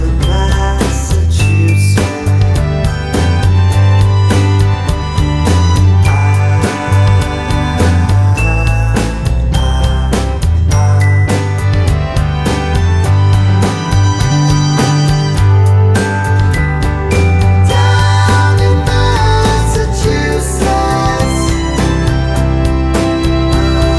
Pop (1960s)